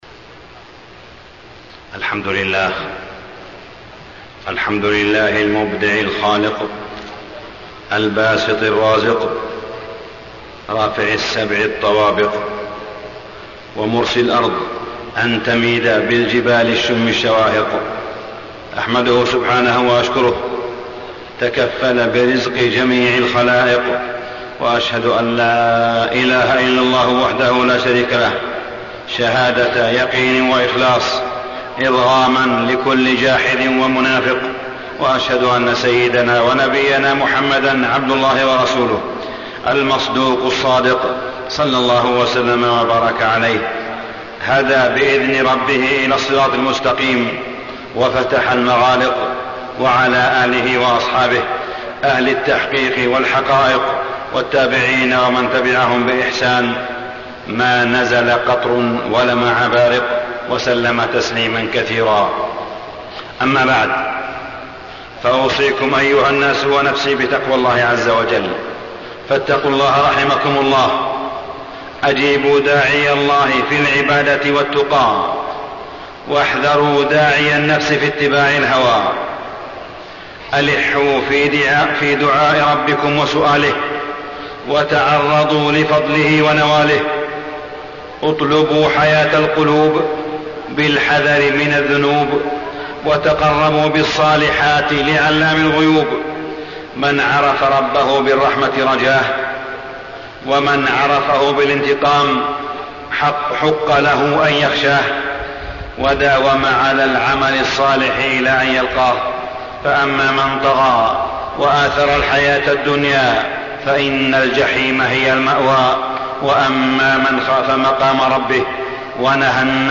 تاريخ النشر ٦ جمادى الأولى ١٤٢٧ هـ المكان: المسجد الحرام الشيخ: معالي الشيخ أ.د. صالح بن عبدالله بن حميد معالي الشيخ أ.د. صالح بن عبدالله بن حميد السماحة